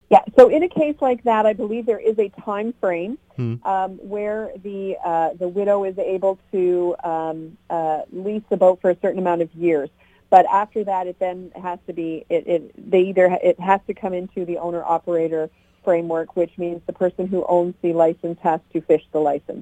La ministre fédérale des pêches, Bernadette Jordan